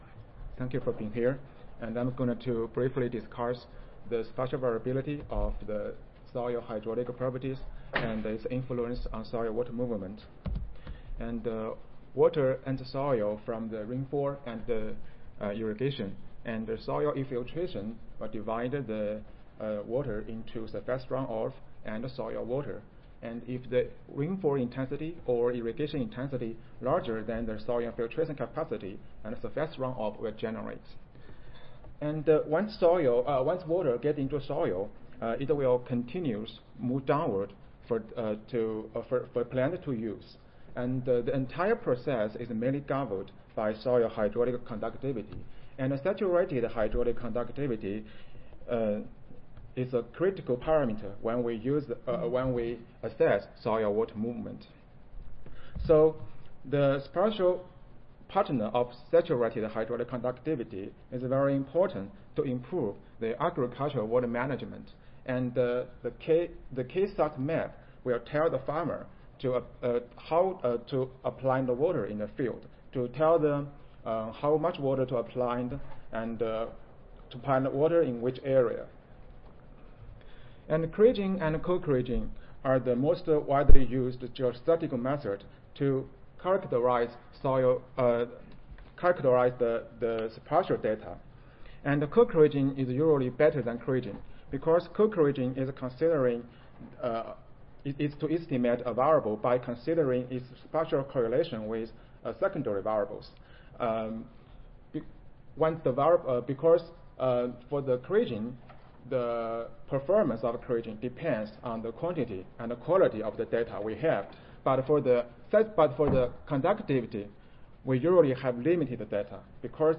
See more from this Division: SSSA Division: Soil and Water Management and Conservation See more from this Session: Soil and Water Management and Conservation General Oral I